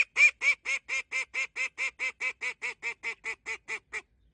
异色柯尔鸭叫声